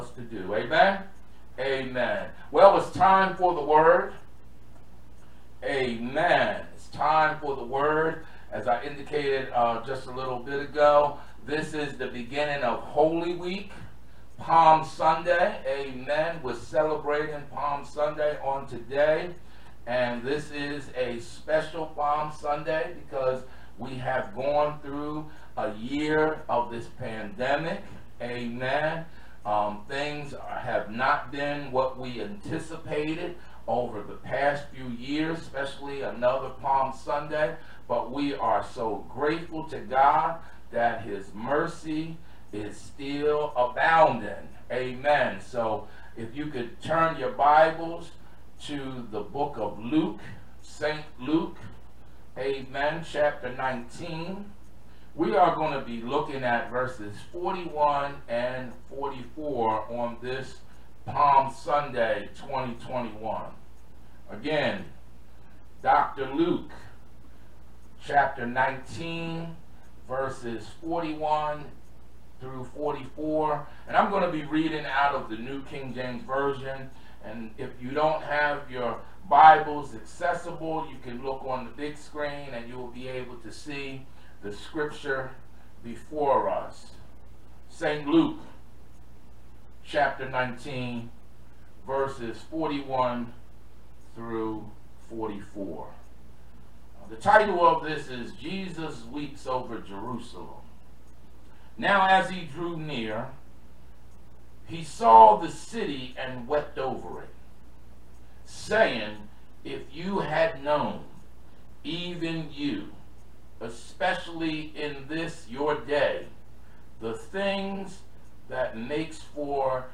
Sermons | Macedonia Baptist Church